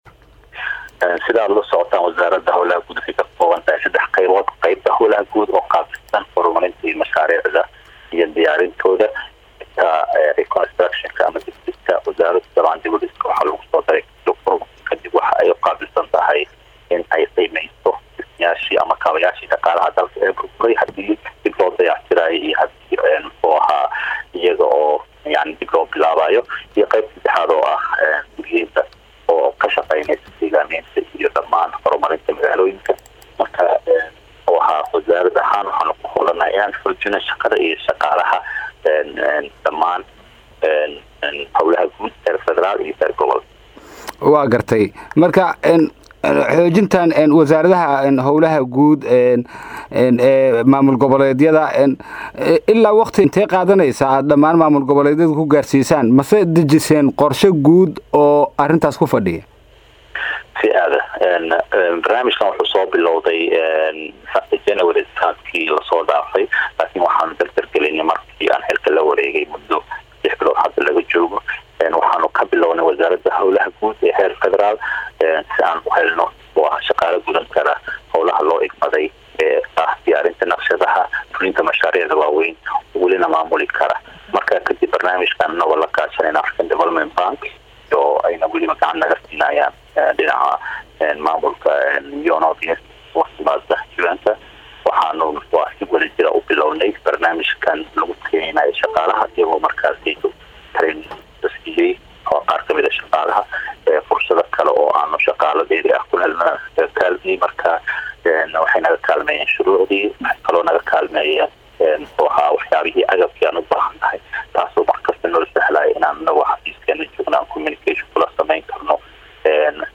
wasiirka wasaarada hawlaha guud iyo dib u dhiska Saadaq C/llahi Cabdi oo wareeysi gar ah siyaay radio muqdisho ayaa sheegay
Halkaan ka Dhageyso Codka wasiirka hawlaha guud iyo dib u dhiska soomaaliya